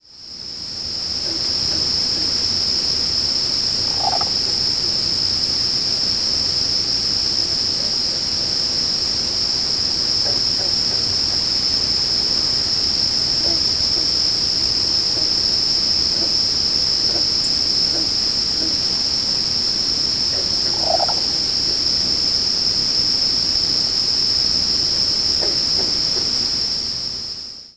presumed Black-billed Cuckoo nocturnal flight calls
Stereo cut with presumed Ovenbird nocturnal flight call and distant chorus of Green Frogs.